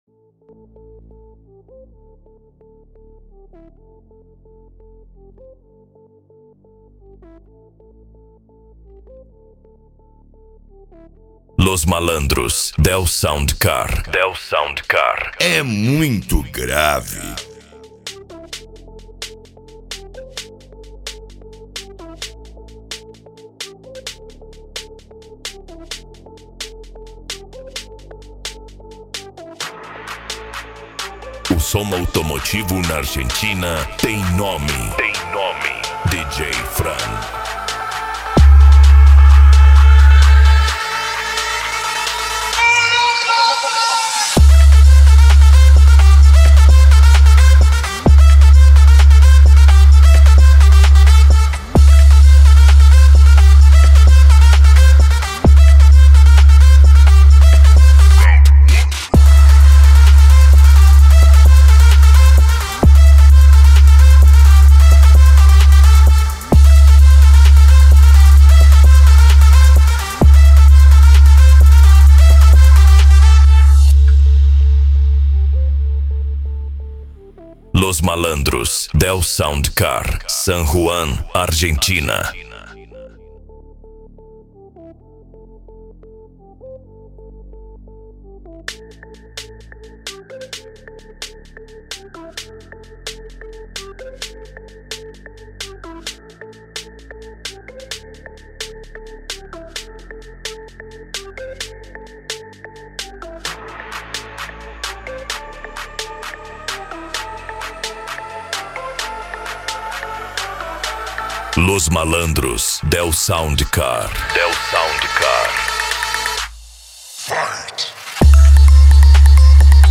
Bass
Eletronica
Psy Trance